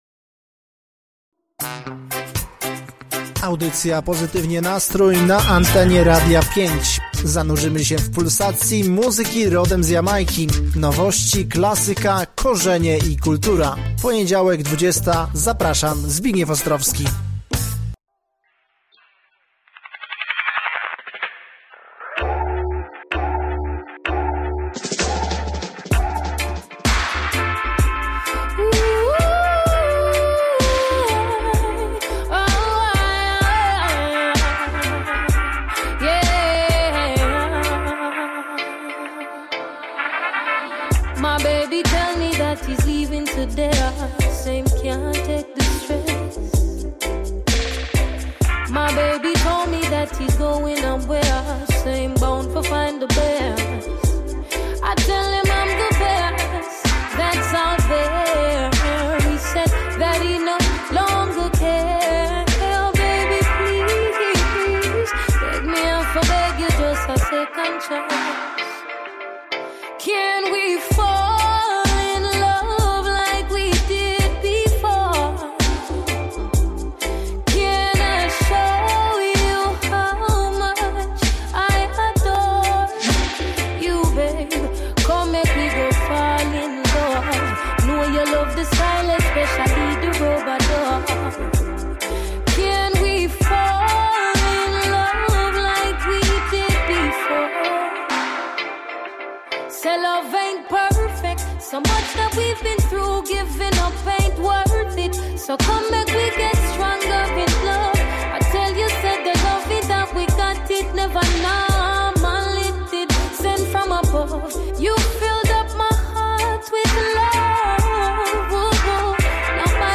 Czas na chwilę relaksu, pozytywnych wibracji i najlepszych brzmień wybranych specjalnie dla Was.